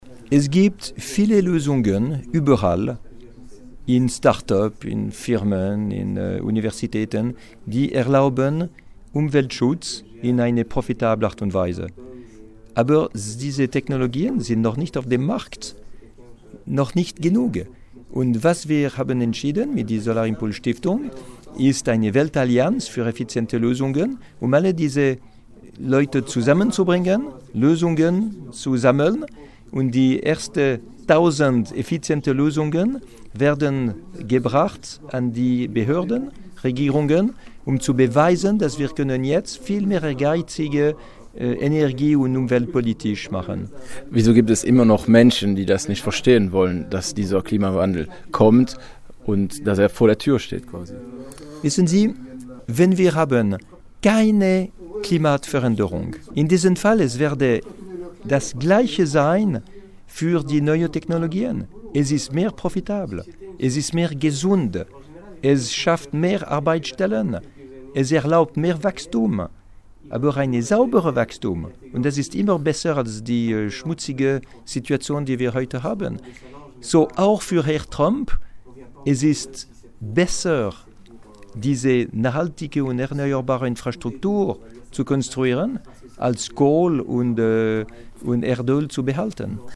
Bertrand Piccard hat als erster Mensch die Welt in einem Solarflugzeug umkreist. Am Freitag war der Luftfahrtpionier in Francorchamps bei den Spark#E-Days.